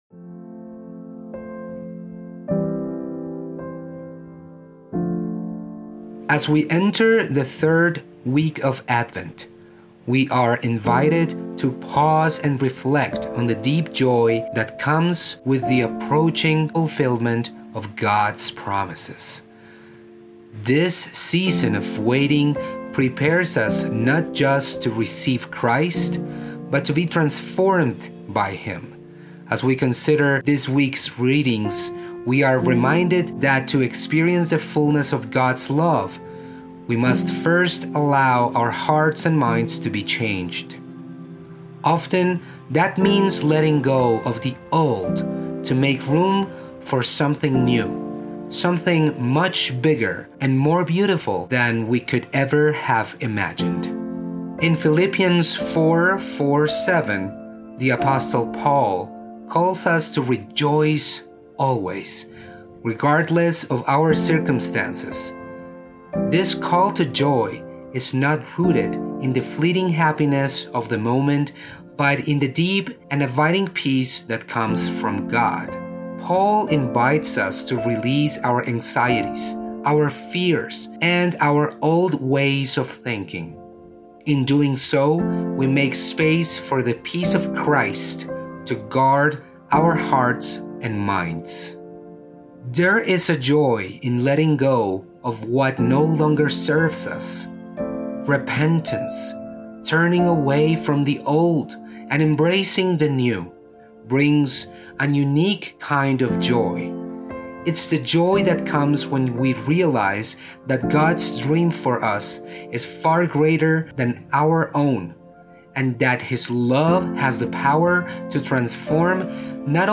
Sermon for December 15, 2024 – Third Sunday of Advent